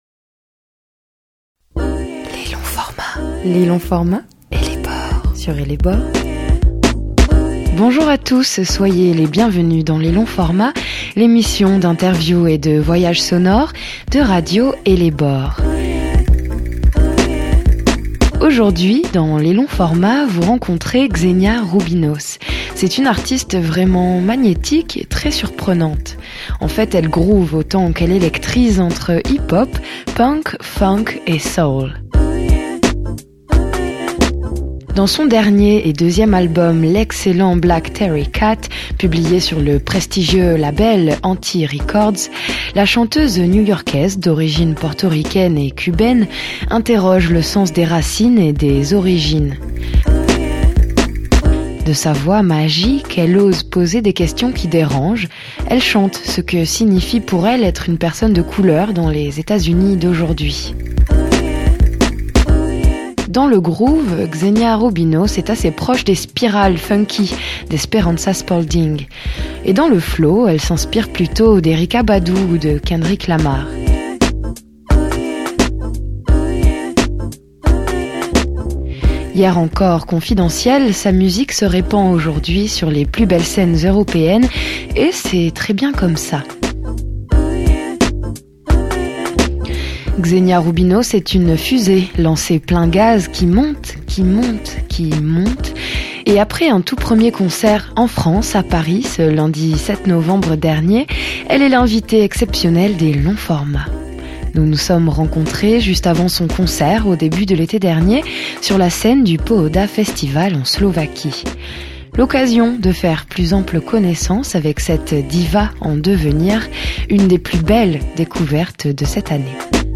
l'interview - Radio Ellebore
Nous nous sommes rencontrées juste avant son concert au début de l’été dernier, sur la scène du Pohoda Festival .
Une interview à écouter ou à lire ci-dessous ( n’hésitez pas à recharger la page si le lecteur n’apparaît pas ). http